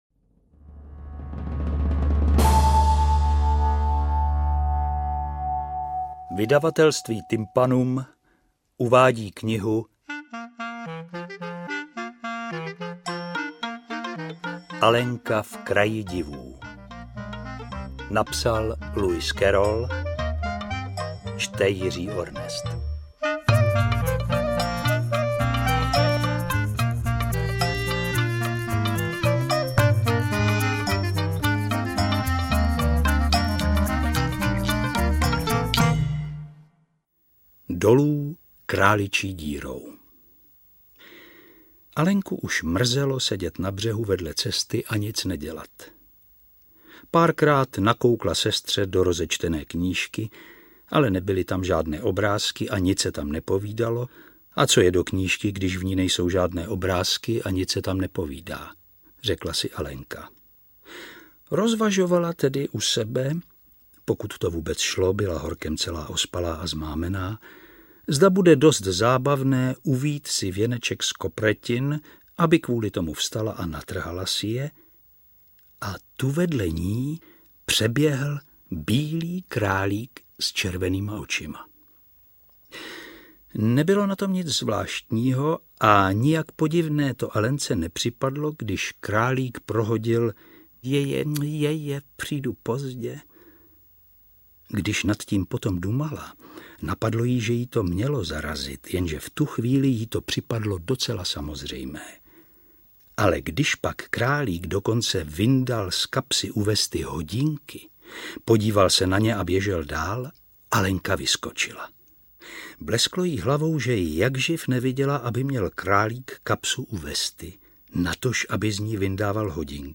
Interpret:  Jiří Ornest
Audiokniha ve formátu MP3. Můžete se poslechnout plnou verzi klasického románu v podání Jiřího Ornesta.